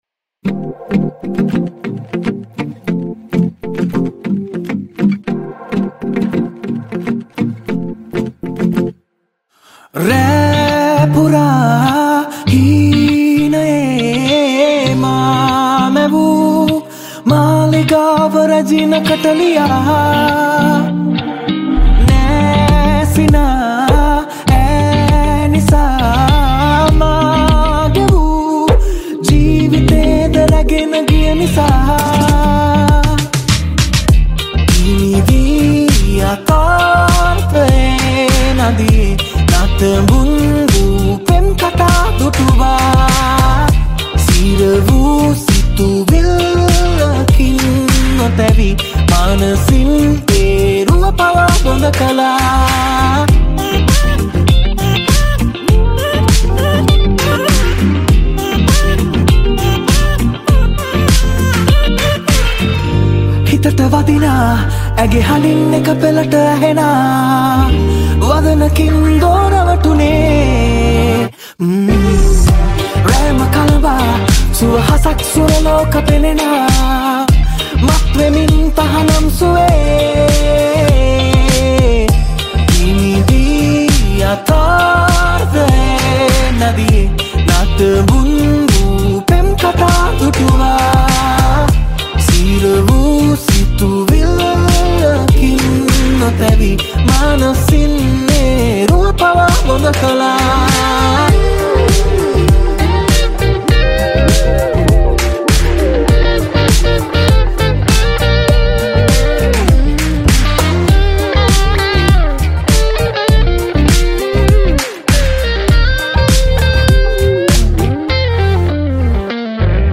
Guitar Riffs
Rhythm & Lead Guitars